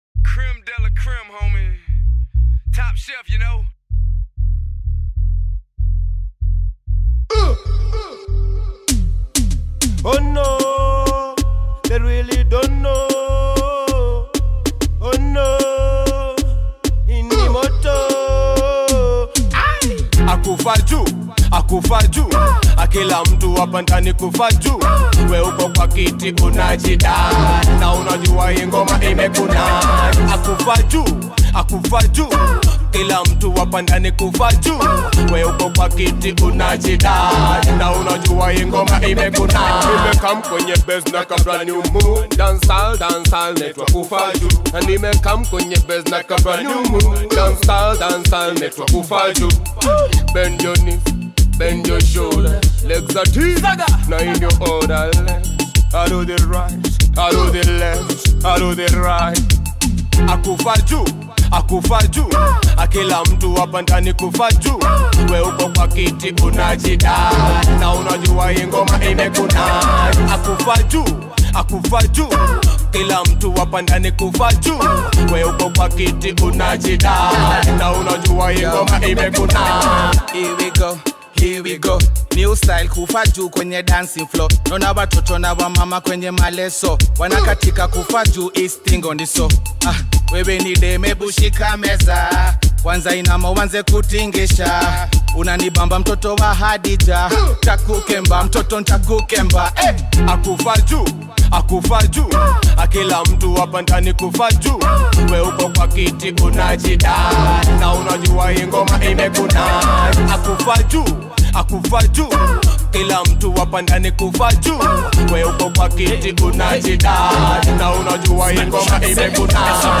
is a mastery of the club scene at its peak